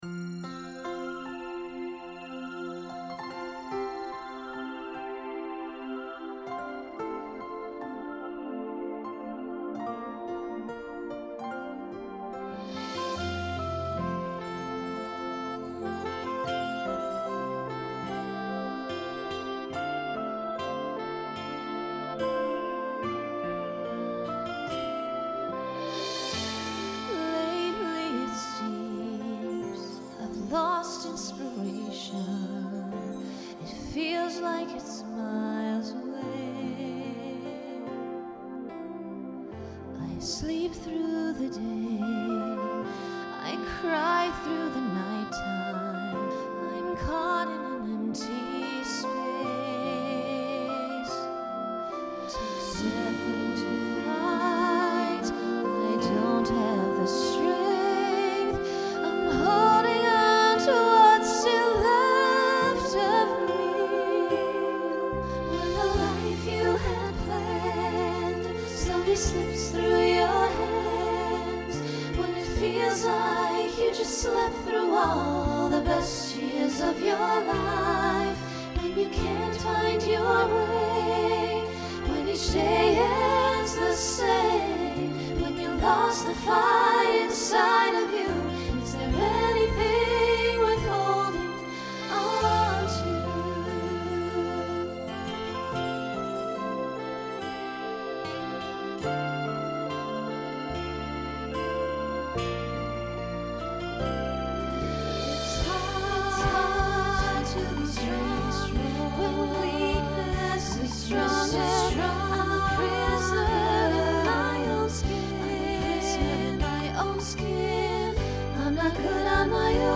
Voicing SSA Instrumental combo Genre Broadway/Film
Show Function Ballad